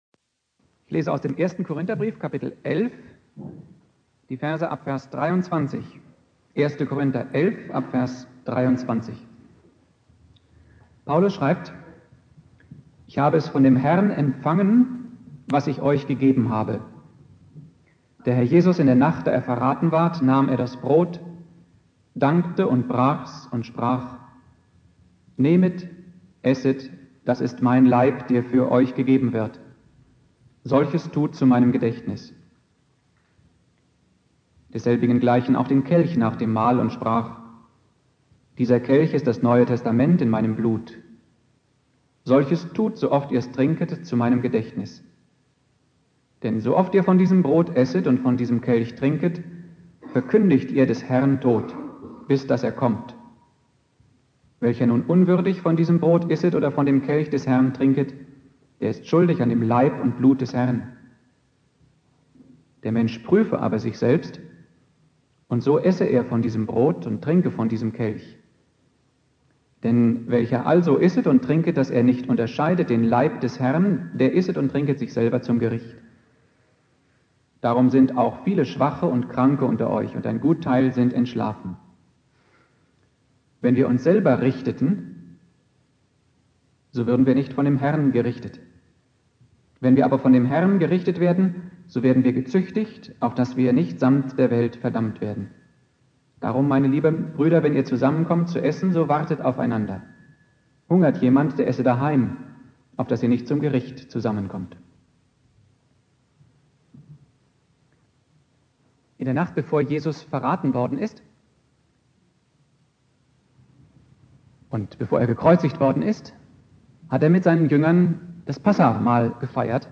Predigt
Gründonnerstag Prediger